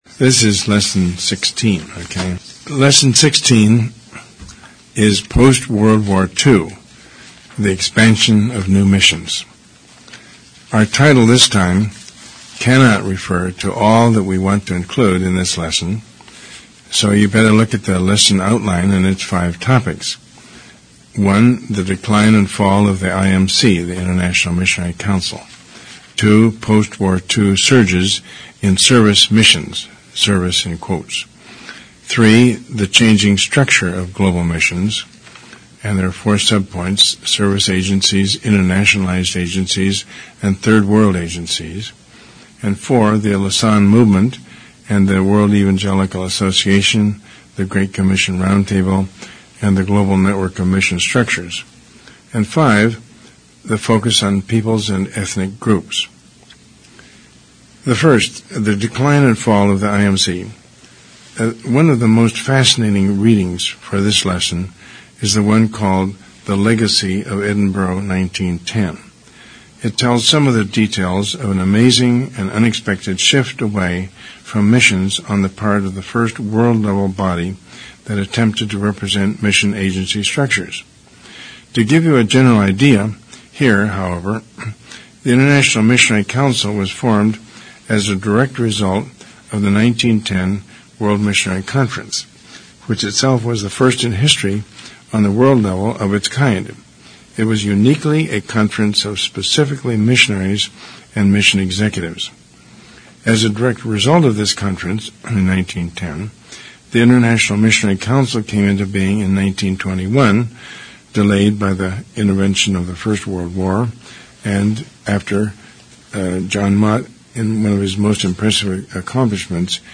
lesson16-lecture.mp3